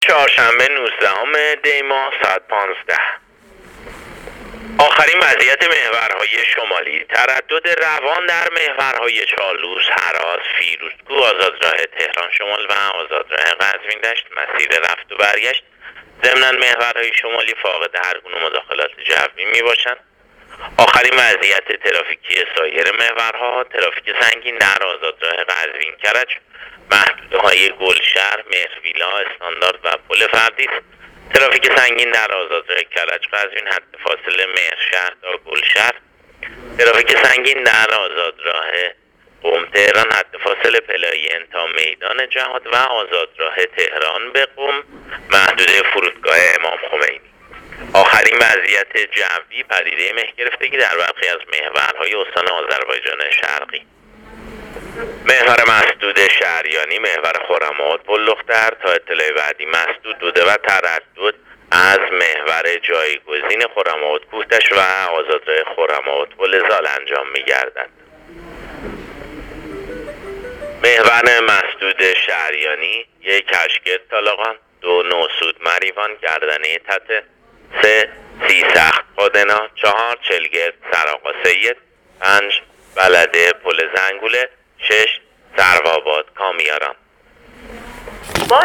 گزارش رادیو اینترنتی از آخرین وضعیت ترافیکی جاده‌ها تا ساعت ۱۵ نوزدهم دی؛